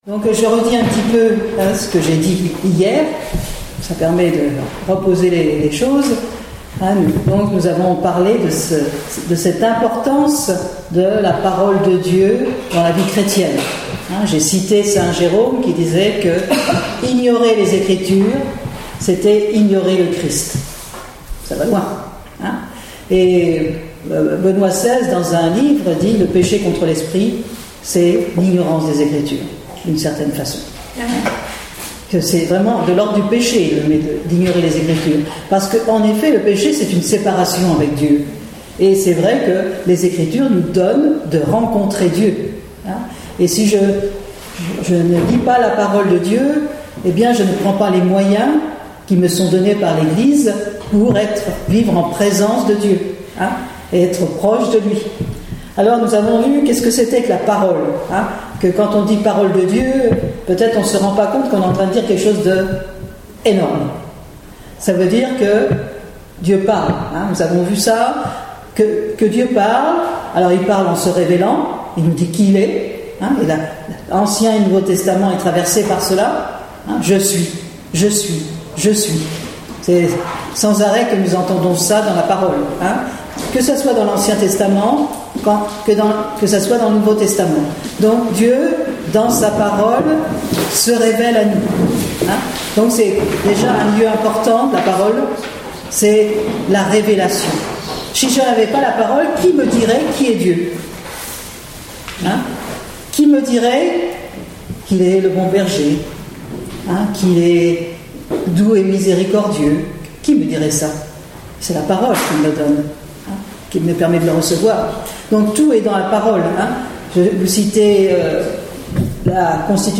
Enseignement
Enregistré en 2017 (Session Béatitudes Lisieux)